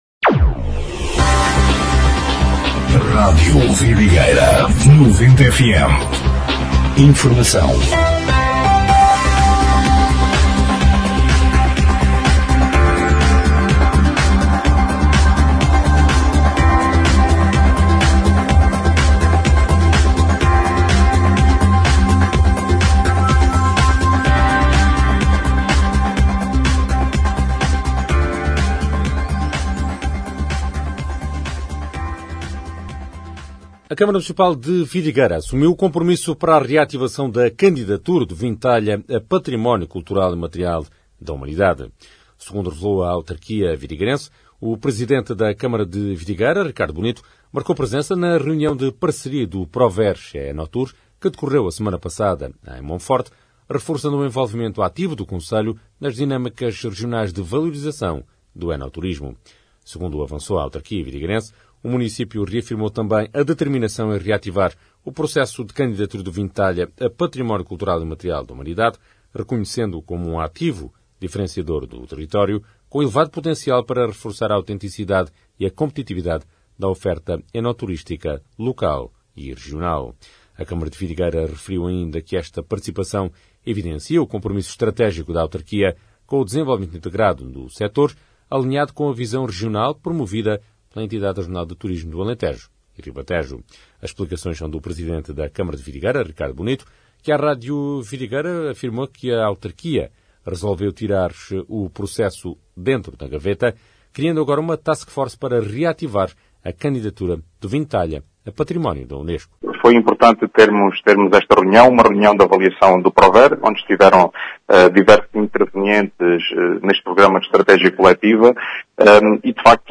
Noticiário 20/04/2026